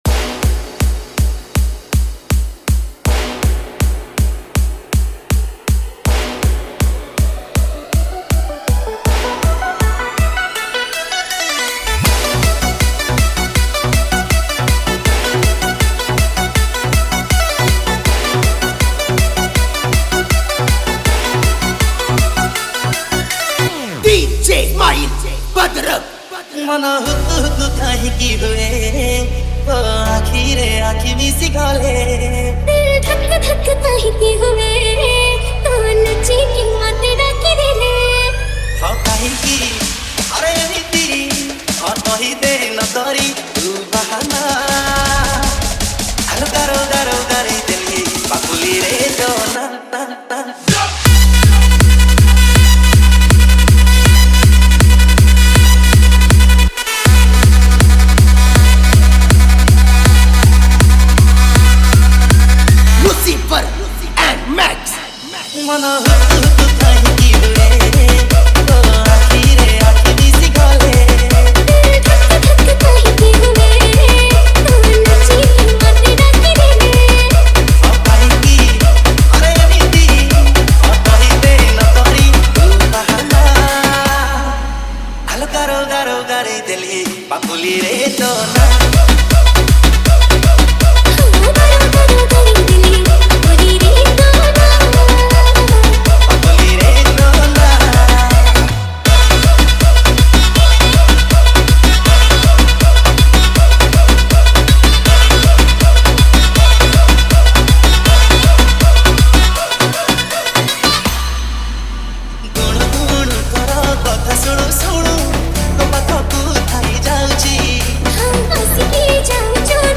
Category:  New Odia Dj Song 2024